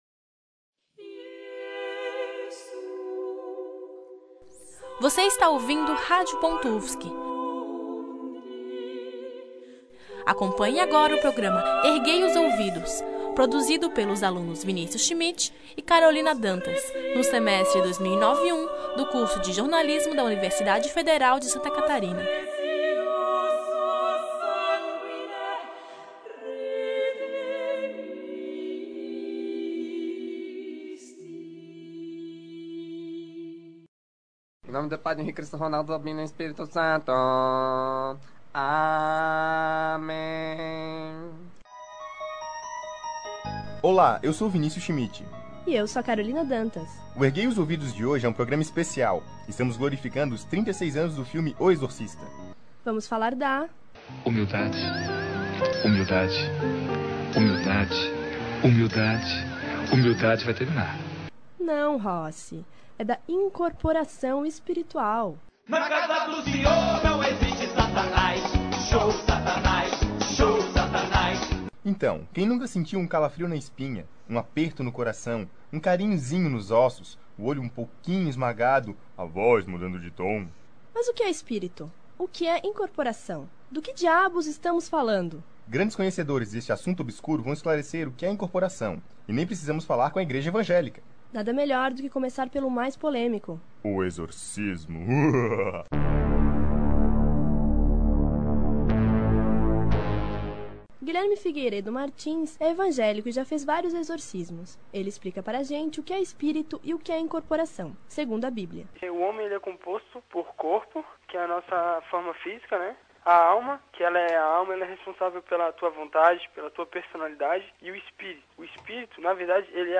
Utilizando músicas e efeitos sonoros, o programa fala de um jeito bem humorado sobre espíritos e incorporação. Dividido em três blocos, o primeiro aborda a visão evangélica e o segundo, a visão da umbanda e do espiritismo. No terceiro bloco, uma entrevista especial com Inri Cristo, que fala sobre incorporações e também de sua vida.